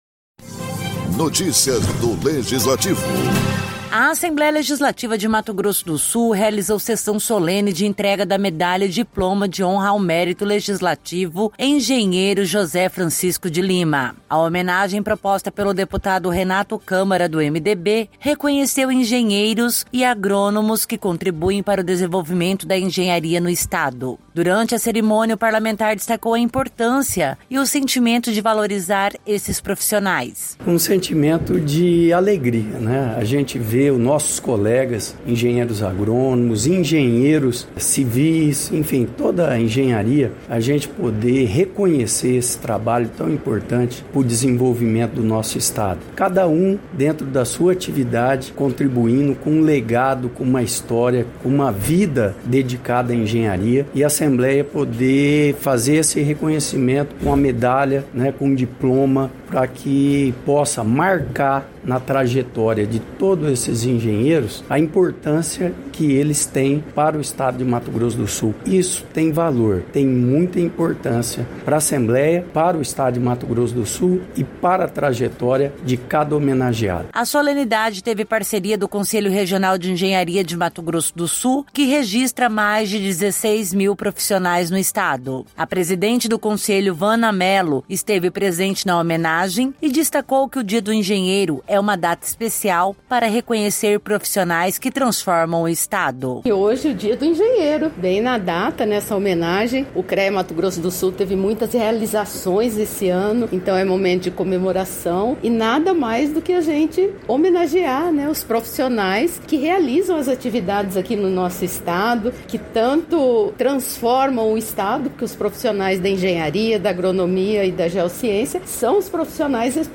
Com o objetivo de reconhecer profissionais que contribuem para o desenvolvimento da engenharia em Mato Grosso do Sul, a Assembleia Legislativa realizou, no Plenário Deputado Júlio Maia, a Sessão Solene de entrega da Medalha e do Diploma de Honra ao Mérito Legislativo Engenheiro José Francisco de Lima.